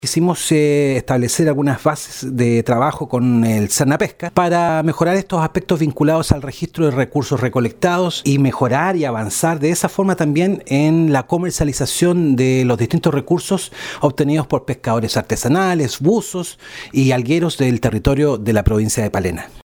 En conversación con Radio Sago,  Carlos Salas, Gobernador de Palena, explicó que este trabajo se inició como consecuencia de los efectos negativos en las economías de decenas de familias de los sectores costeros e insulares de la comuna de Chaitén, afectados por la escasa demanda fundamentalmente de mariscos de la zona,  a pesar de que esta área siempre estuvo libre del fenómeno de la Marea Roja. El Gobernador Salas precisó que esta acción busca contar con un referente Público y Privado de coordinación en las diferentes acciones del Estado, facilitando el desarrollo de la actividad vinculada a la extracción y manejo de los recursos marinos.
CUÑA-GOBERNADOR-DE-PALENA-2-.mp3